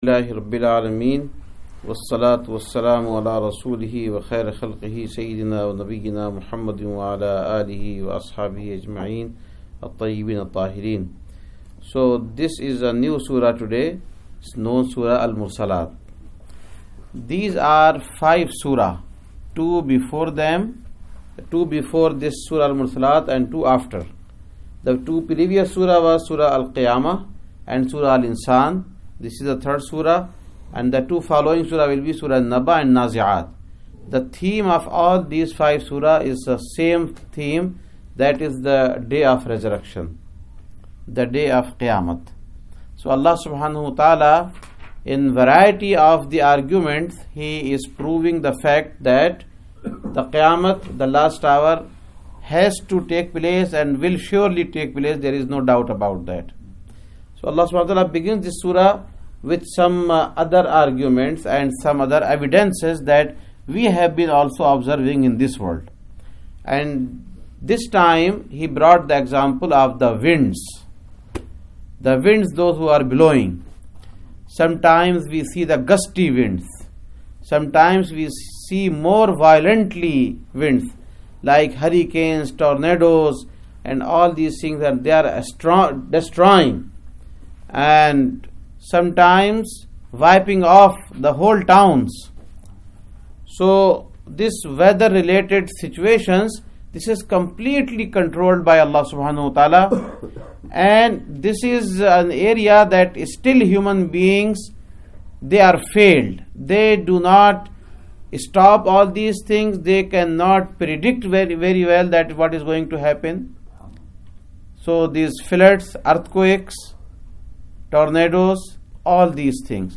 #30 Quran Class - Al-Mursalat Ayyat 01-12
#30 Quran Class - Al-Mursalat Ayyat 01-12 Adult Quran Class conducted on 2015-04-26 at Frisco Masjid.